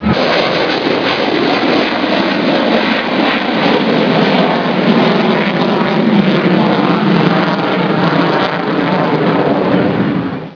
sound fighter.wav